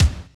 drumOn.wav